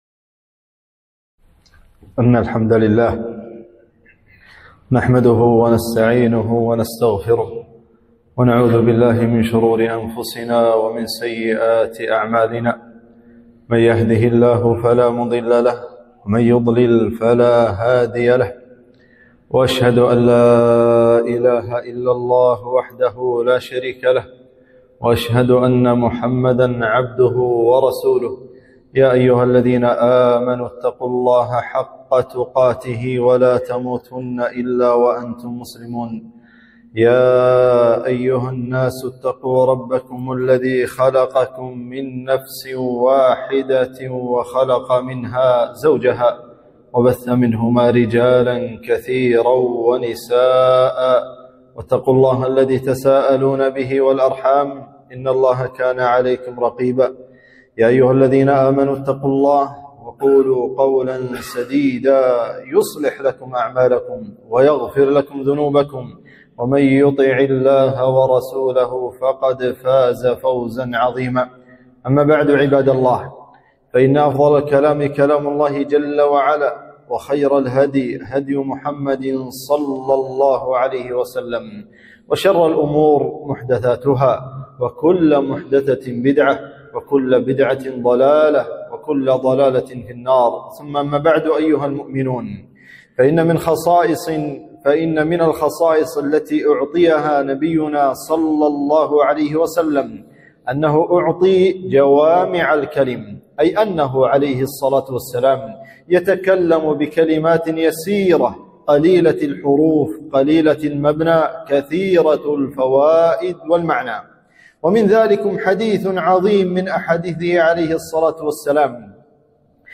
خطبة - ( اتق الله حيثما كنت)